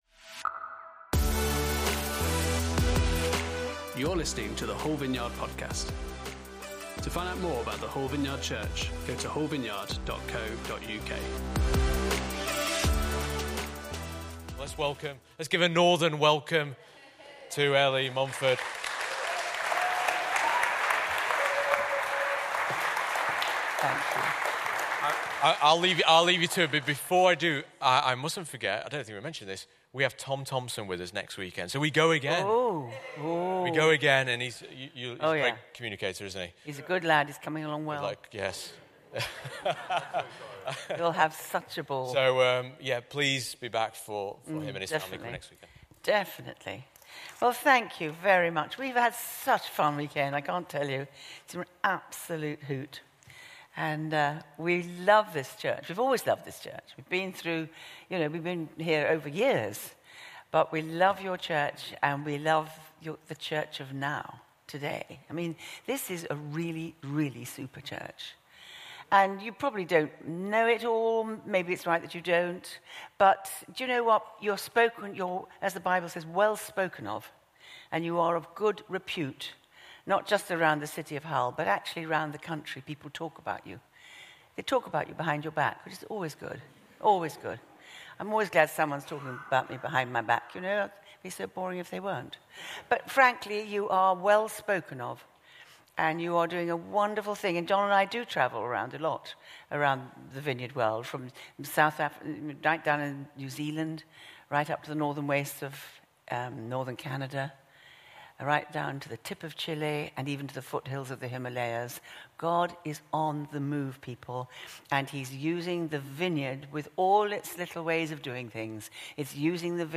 Guest Speaker
Service Type: Sunday Service